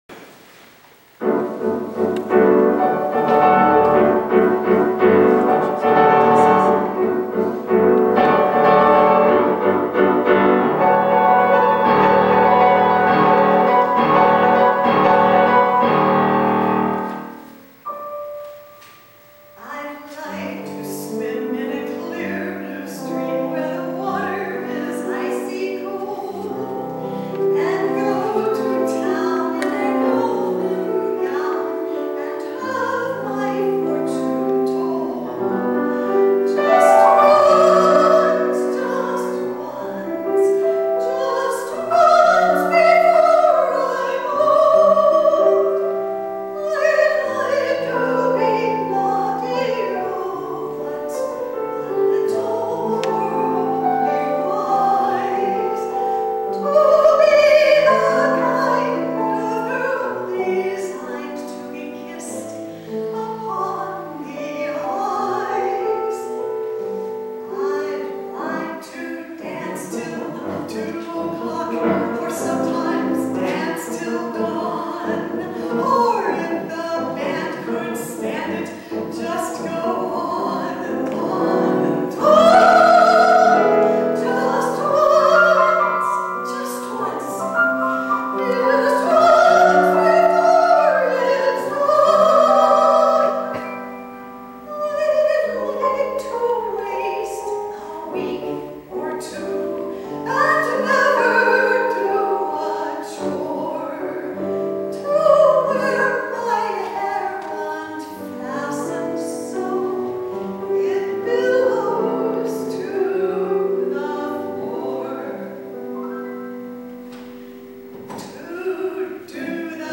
Performing at 2000 concert
In 2000, I peformed a concert in Palo Alto, CA. Below are audios of what I sang by clicking on a title listed below.